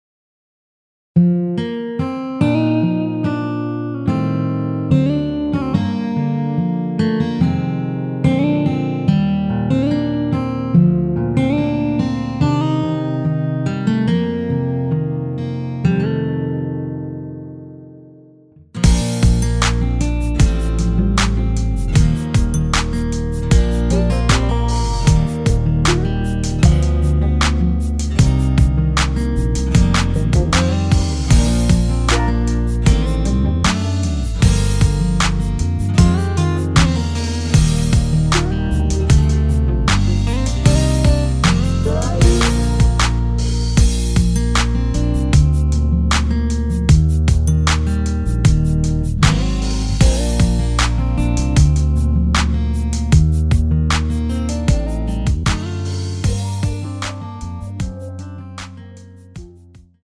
원키에서(-5)내린 MR입니다.
Bb
앞부분30초, 뒷부분30초씩 편집해서 올려 드리고 있습니다.